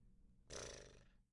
黑暗中的木头的声音 " 笑声
描述：女孩的笑声
标签： 动作 声音 神秘 黑暗
声道立体声